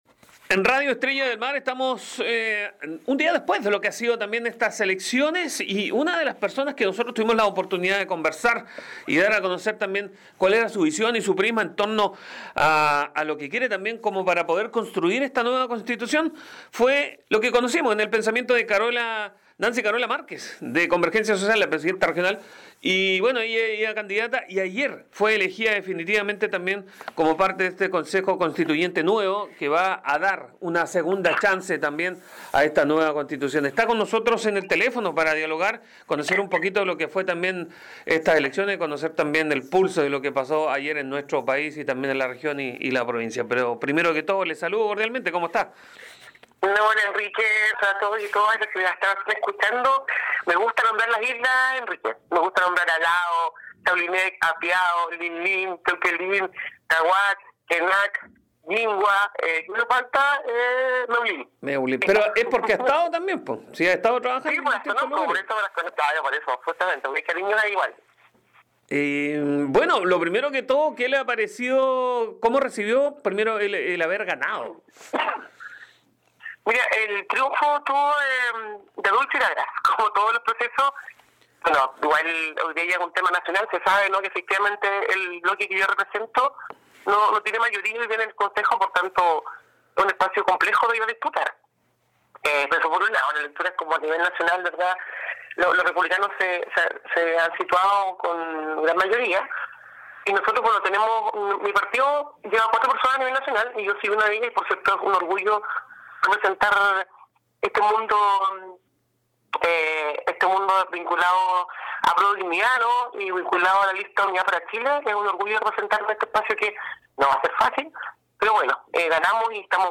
conversa con una de las candidatas electas tras la jornada de comicios del día domingo